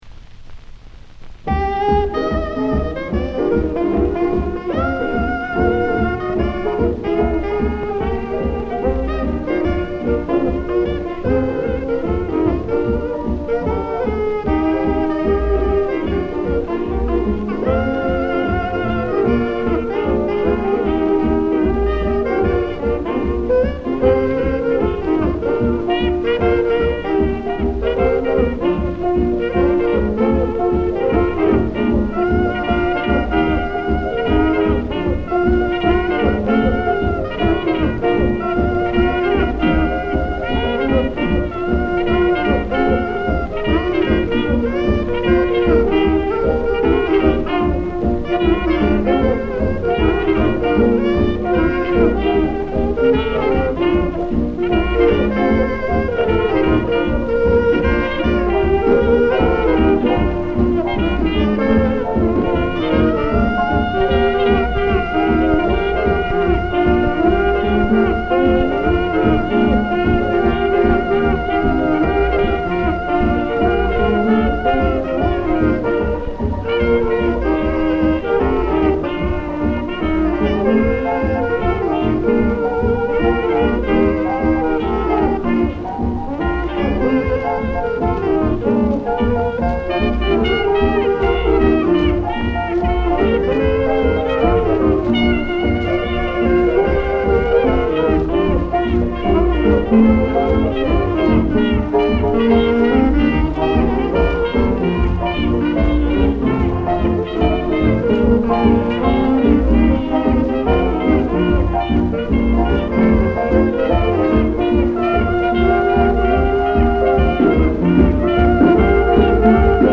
recorded 1953
78 rpm
mono
alto sax
trumpet
tenor sax